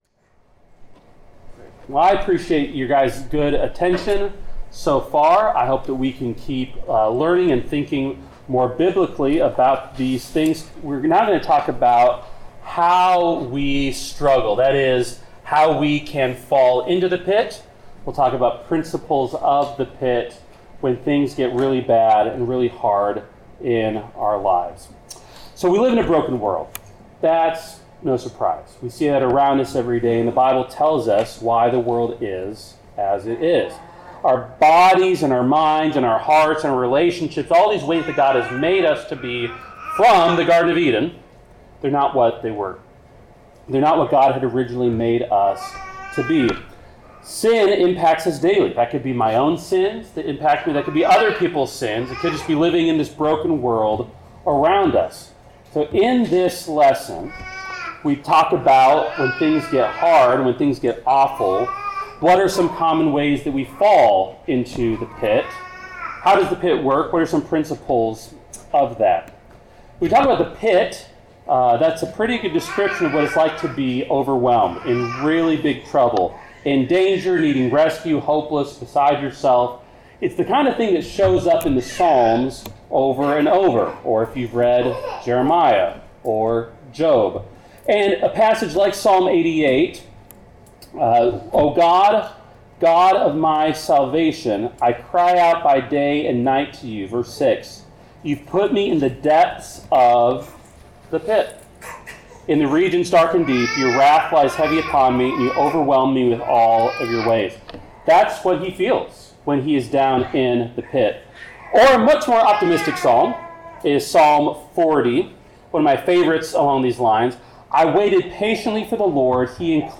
Series: GROW Conference 2025 Service Type: Sermon